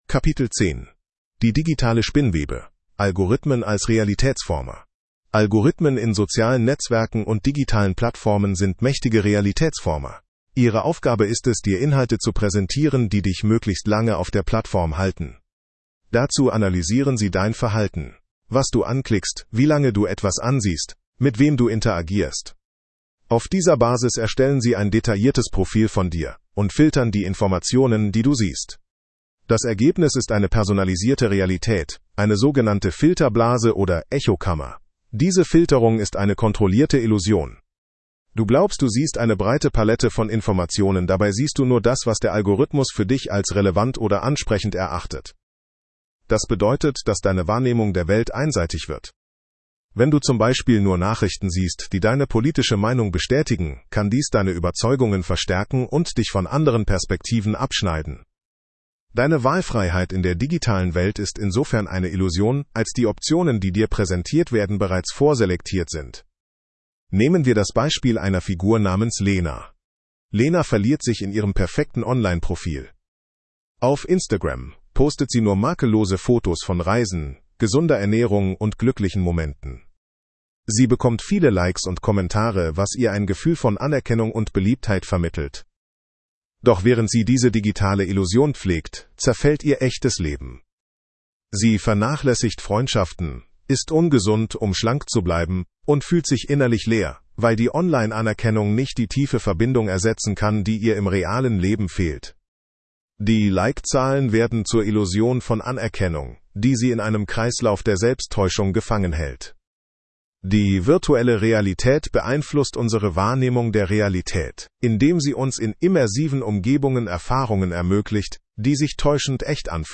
Erhältlich als Hörbuch auf Google Play und Storytel
ki-audio-horprobe-bewusstsein.mp3